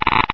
geiger6.ogg